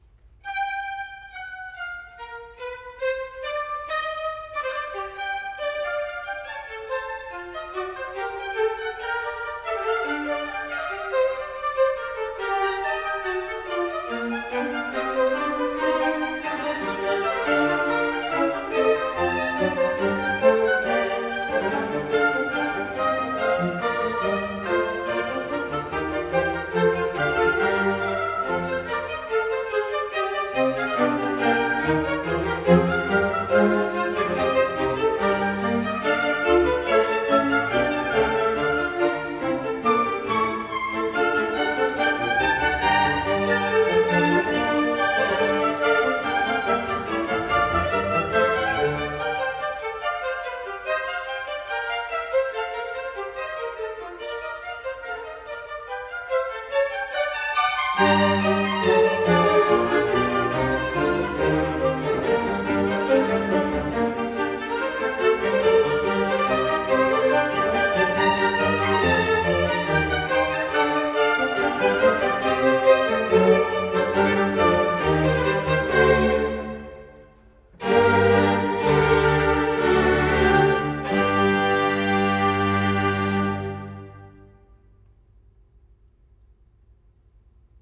G minor - A tempo giusto